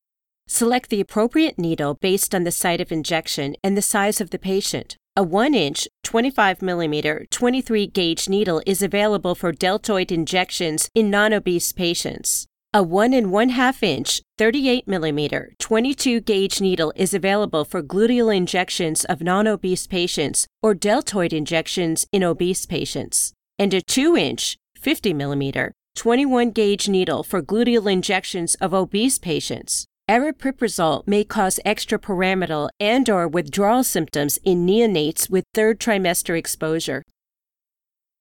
friendly, relaxed, authoritative, believable
Sprechprobe: eLearning (Muttersprache):